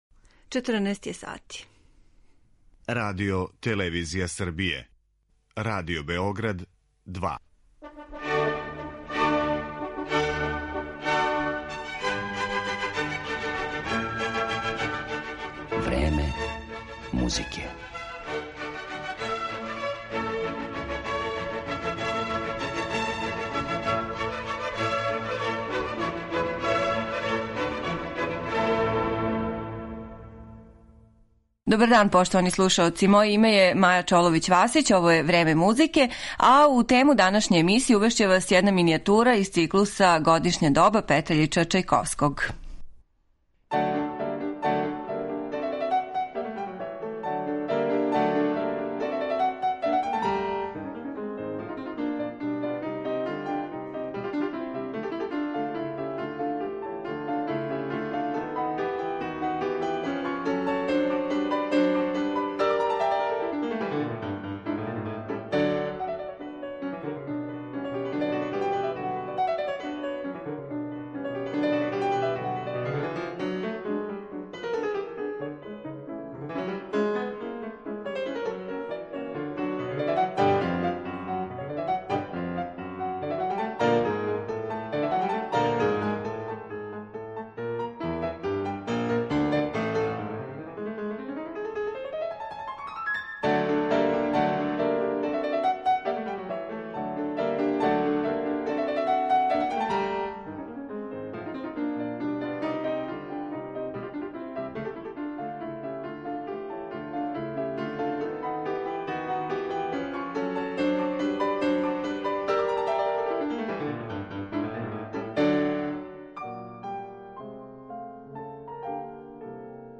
Фрагменти из њихових „карневалских" остварења чине садржај данашње емисије